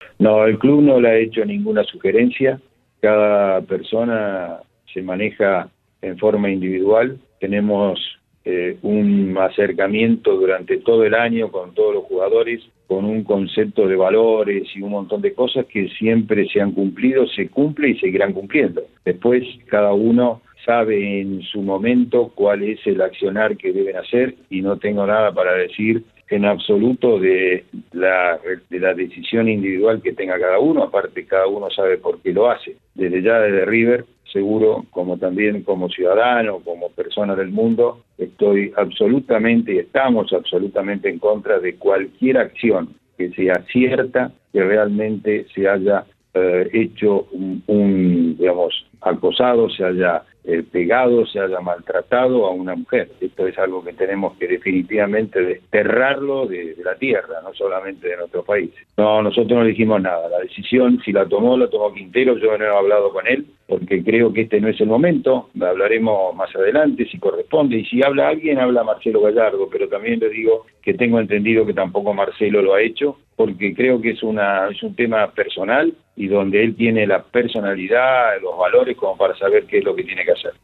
Rodolfo-DOnofrio-presidente-de-River-Plate-2.mp3